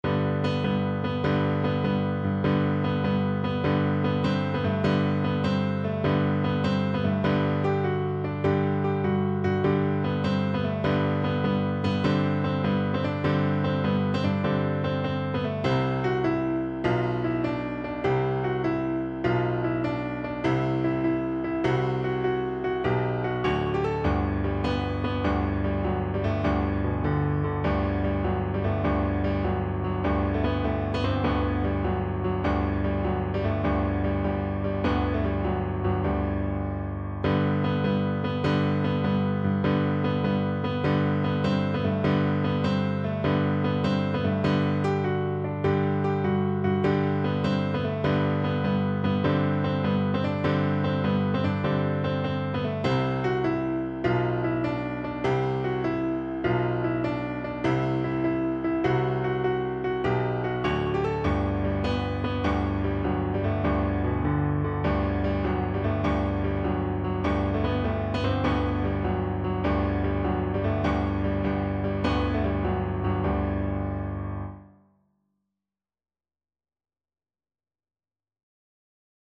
= 100 Allegro assai (View more music marked Allegro)
6/8 (View more 6/8 Music)
Classical (View more Classical Cello Music)